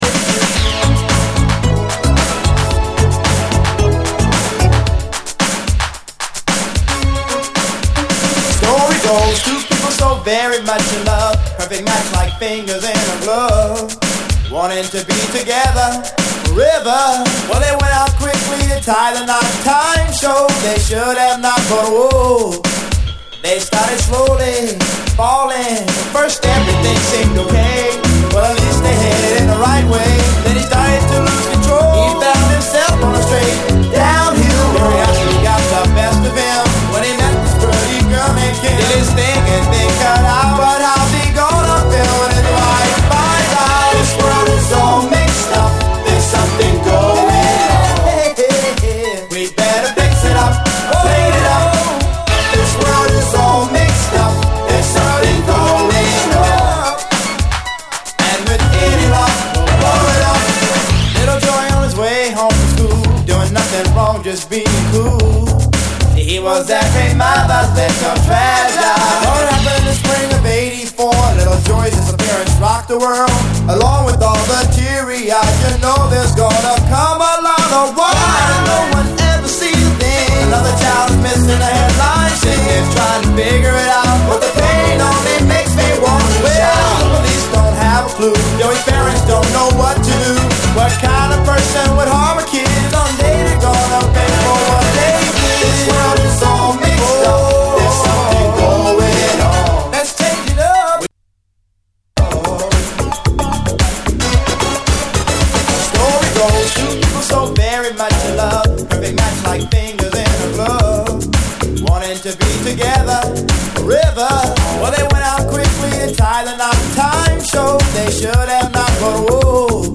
厳かなオルガンからジワジワと盛り上がる、独特な一曲！